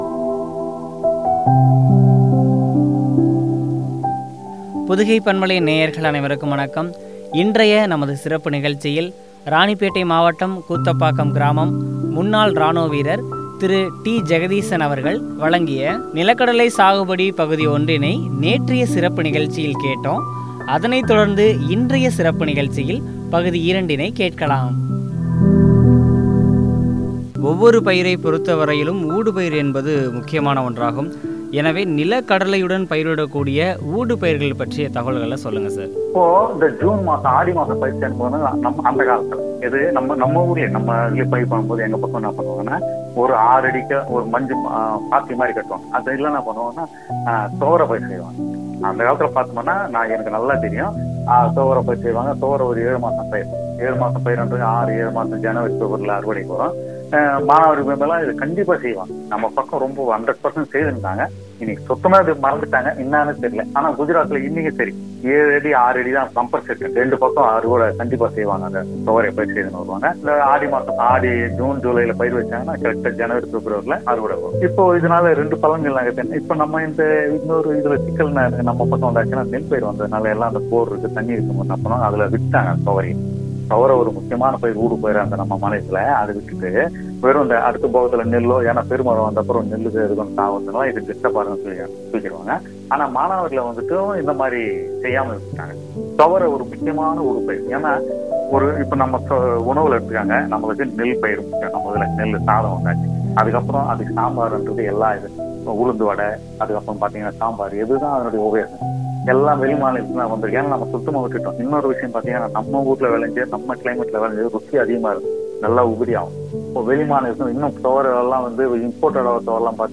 பற்றி வழங்கிய உரையாடல்.